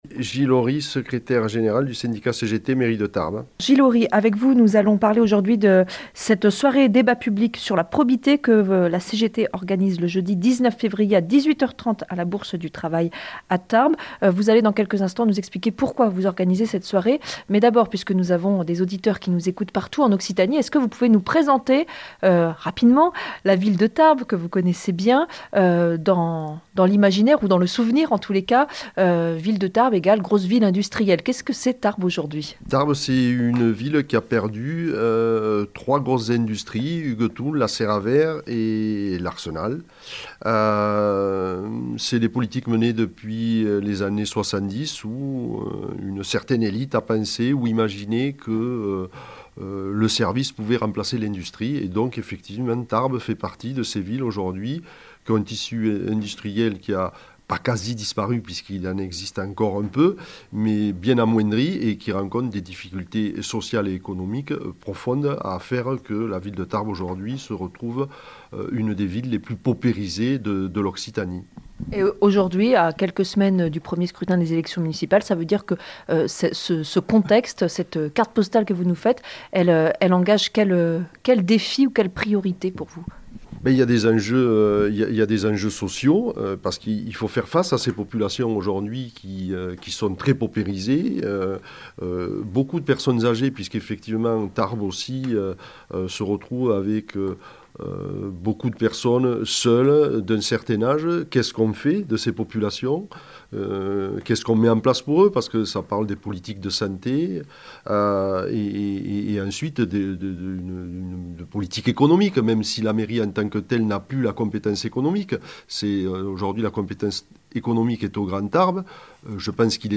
Accueil \ Emissions \ Information \ Locale \ Interview et reportage \ Avant les élections municpales, soirée débat avec tous les candidats à Tarbes.